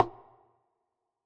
SNARE - SHED.wav